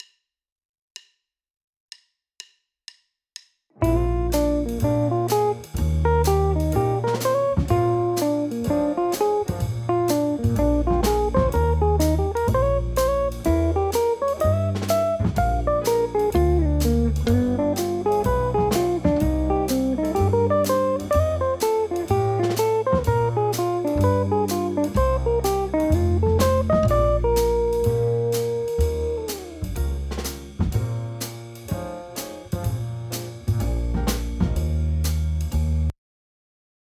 • 1 Jazz Backing Track